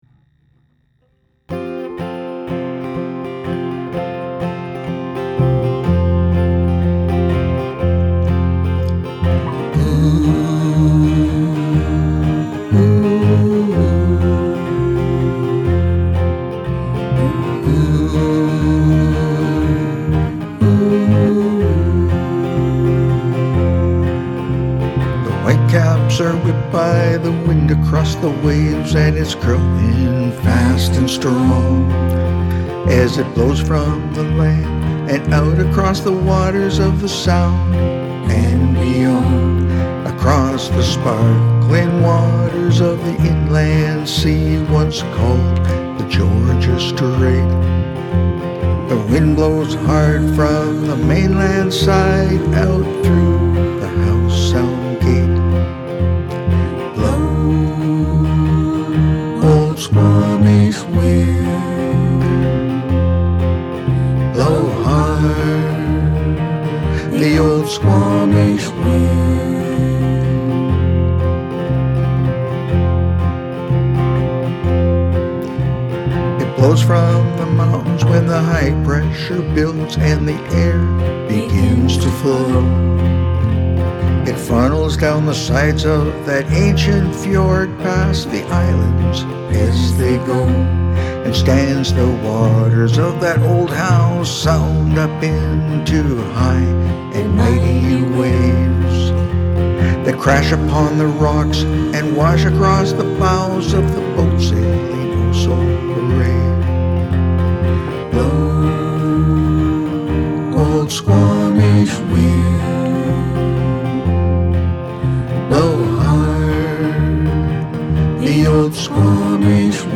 Having new fresh voicing in it has given it new life.